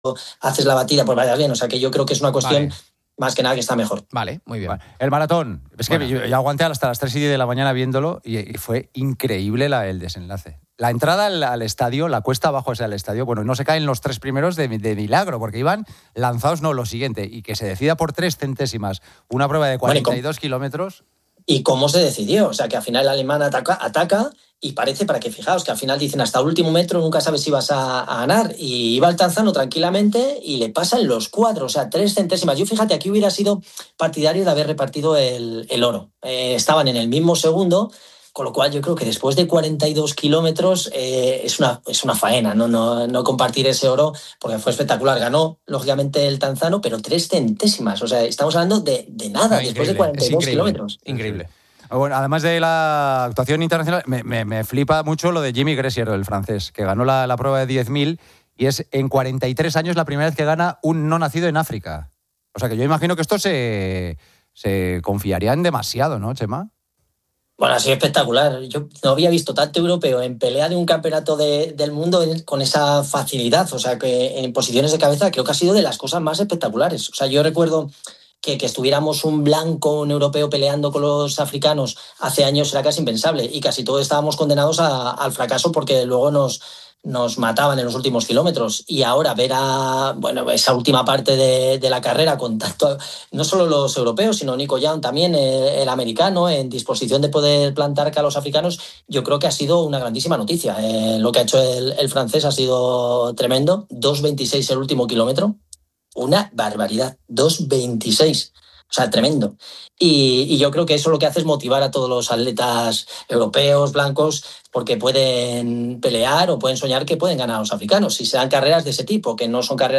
Oyentes preguntan sobre la preparación para un 10k tras una lesión, la posibilidad de un maratón sub-3 horas y consejos para el inicio de temporada.